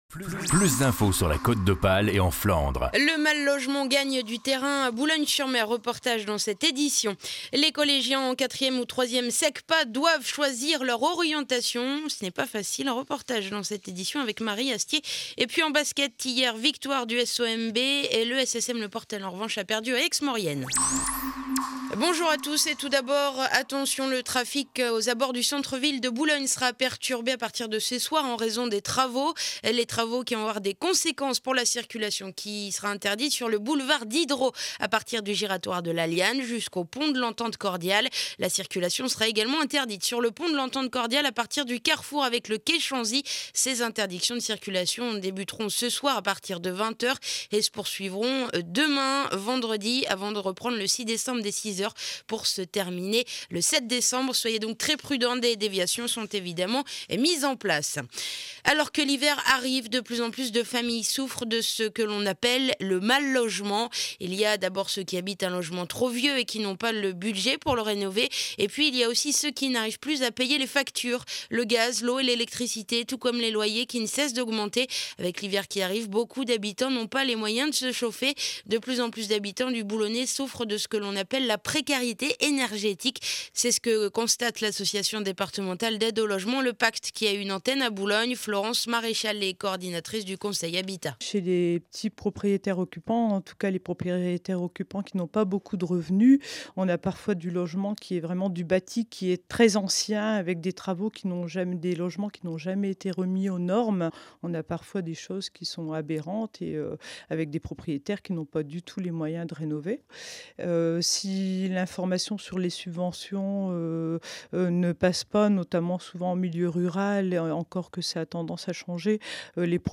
journal du mercredi 30 novembre édition de 8h30 à Boulogne sur mer